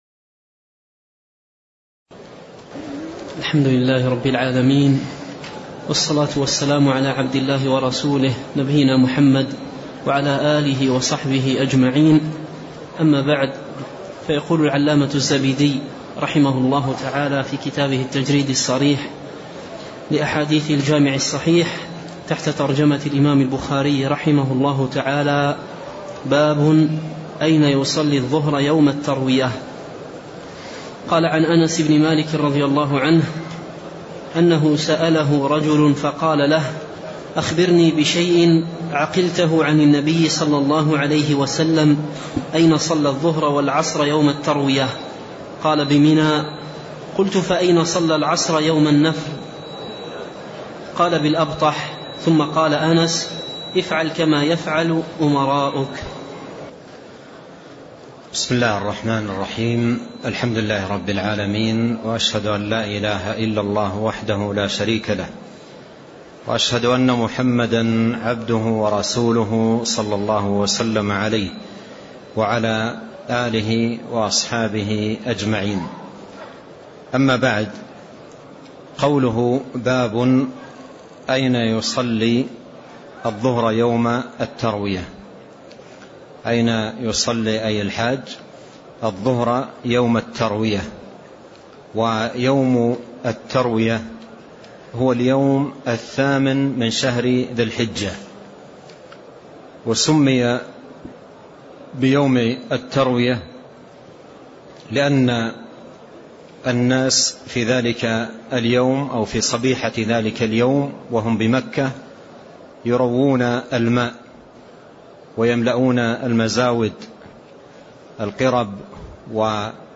تاريخ النشر ٢٢ ذو القعدة ١٤٣٤ هـ المكان: المسجد النبوي الشيخ